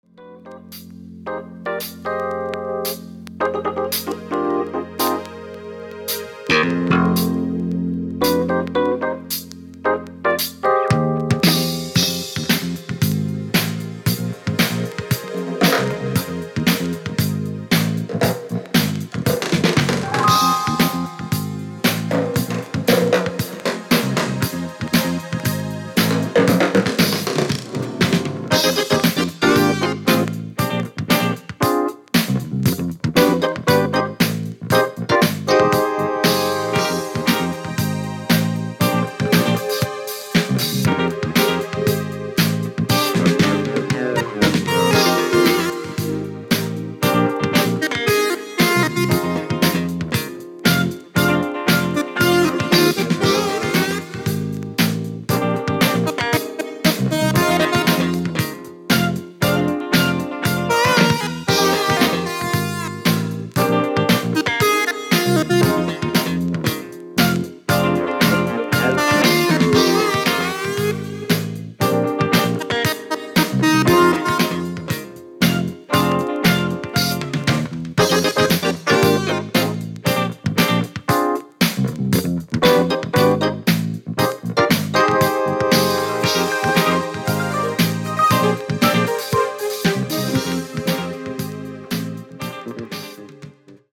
Funk and Soul group